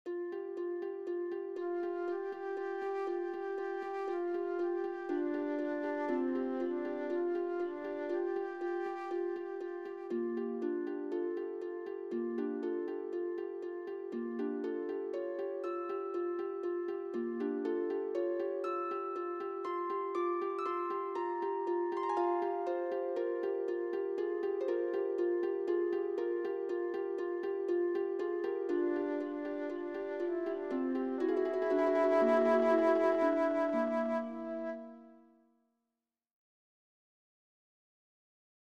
Carácter de la pieza : cambio de tiempo